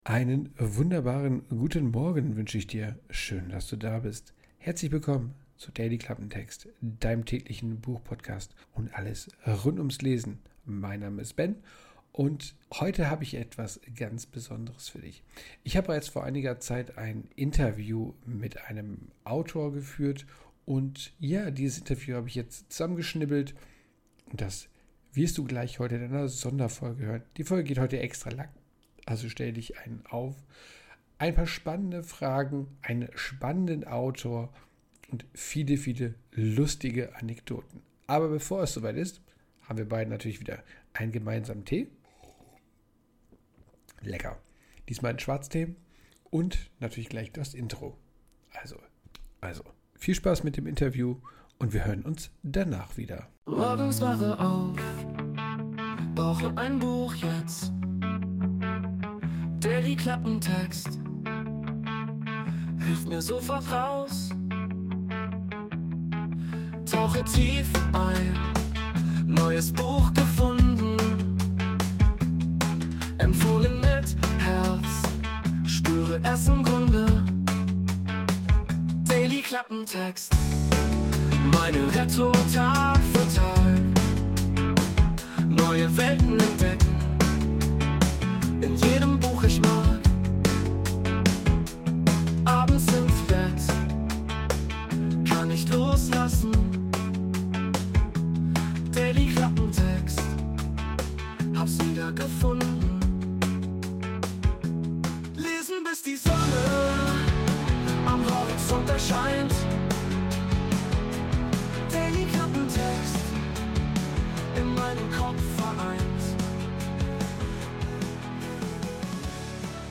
Es gibt ein Interview.
Intromusik: Wurde mit der KI Suno erstellt.